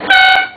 Horn
ferrari308_horn.wav